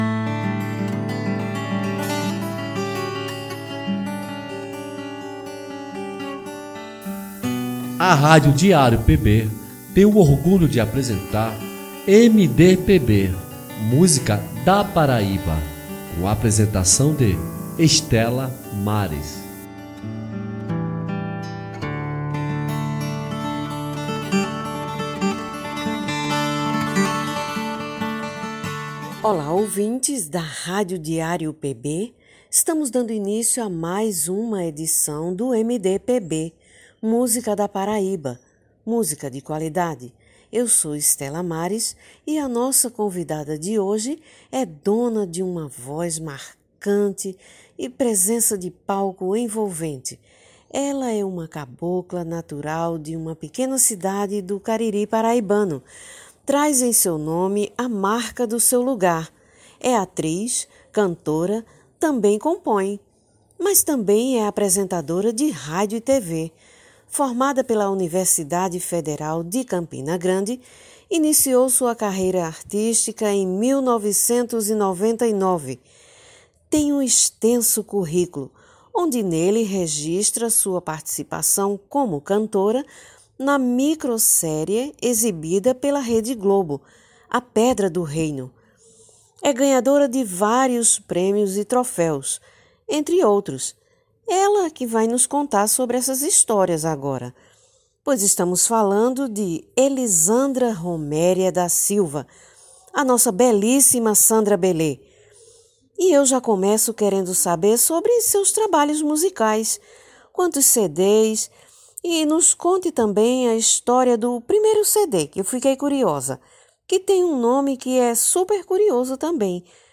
entrevista exclusiva